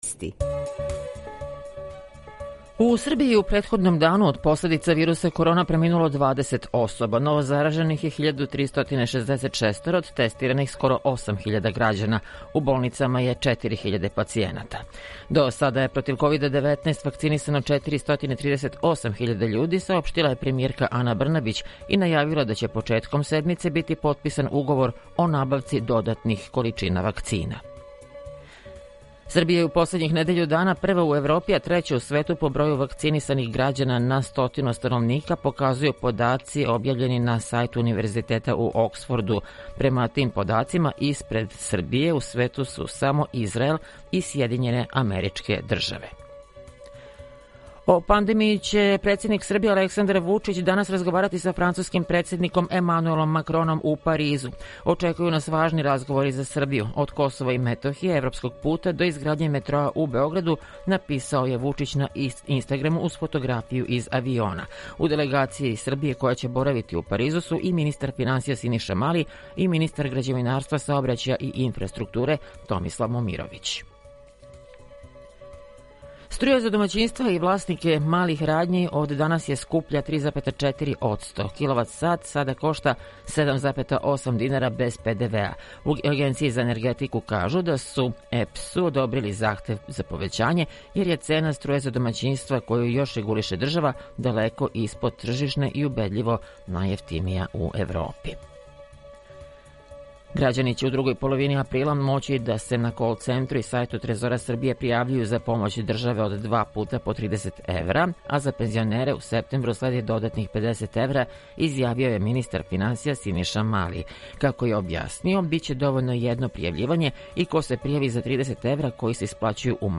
Јутарњи програм из три студија
Jутарњи програм заједнички реализују Радио Београд 2, Радио Нови Сад и дописништво Радио Београда из Ниша.
У два сата, ту је и добра музика, другачија у односу на остале радио-станице.